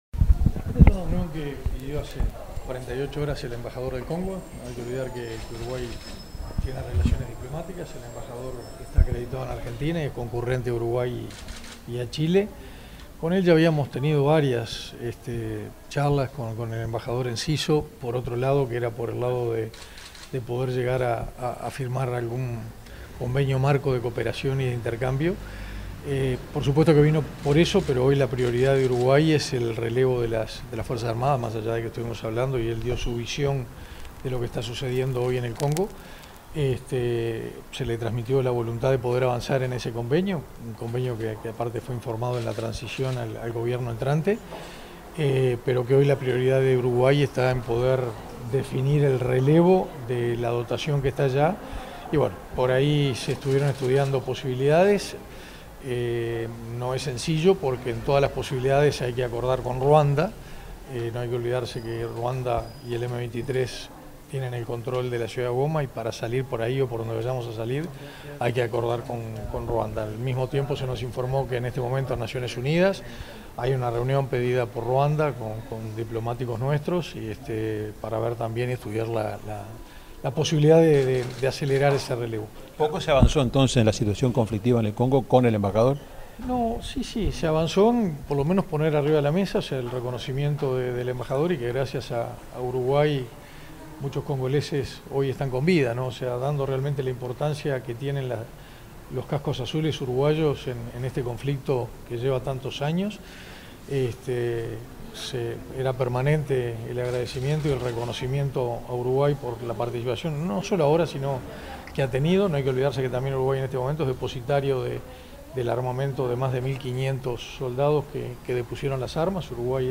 Declaraciones del ministro de Defensa Nacional, Armando Castaingdebat
El ministro de Defensa Nacional, Armando Castaingdebat, efectuó declaraciones a la prensa acerca de la situación de los soldados uruguayos en el Congo